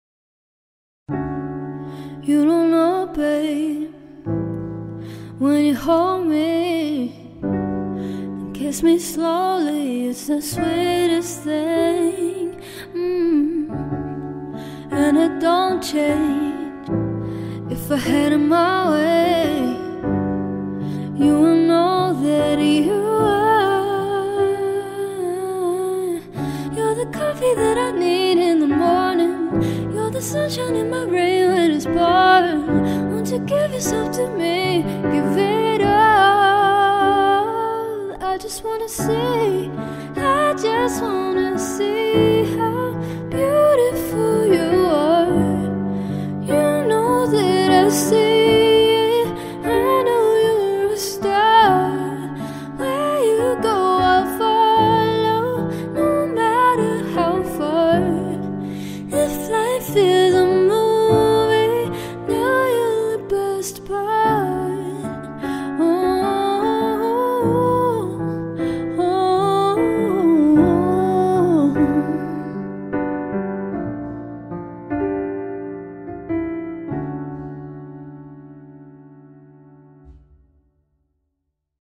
Vocals | Guitar | Looping | DJ